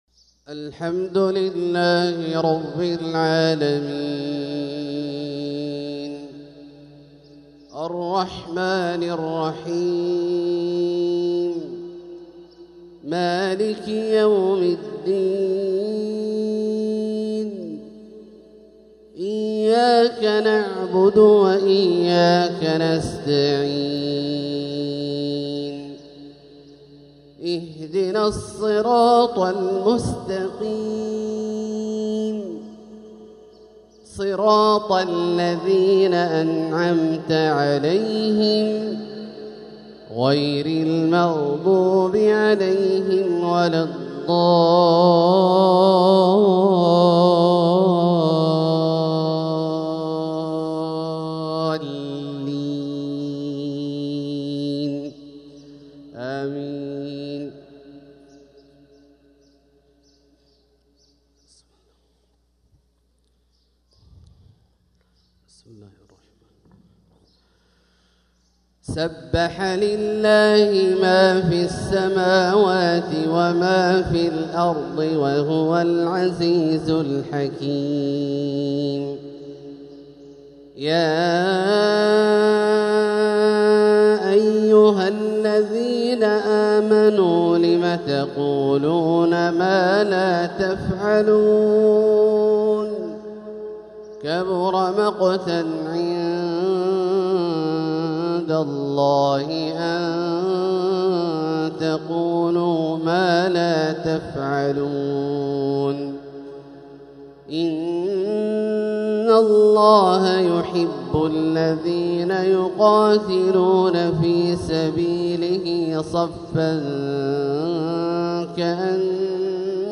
تلاوة جميلة لسورة الصف | فجر الأحد 5-7-1446هـ > ١٤٤٦ هـ > الفروض - تلاوات عبدالله الجهني